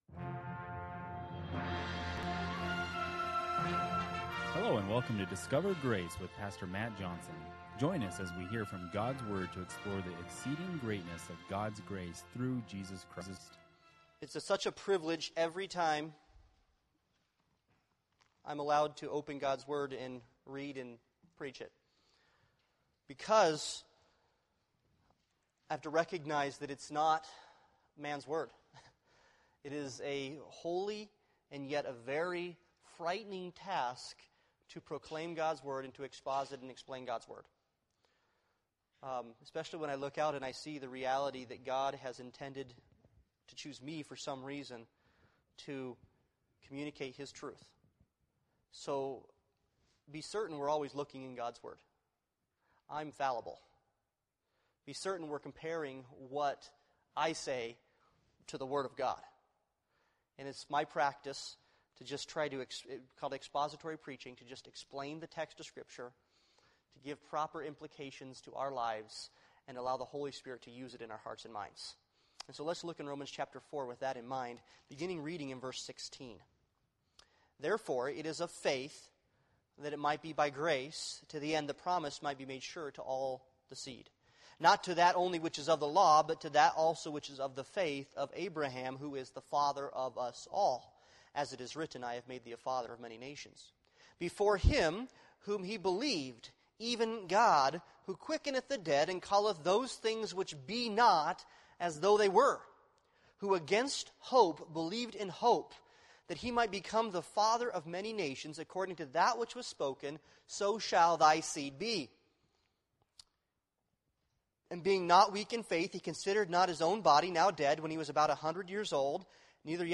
Romans 4:16-25 Service Type: Sunday Morning Worship « No Wrath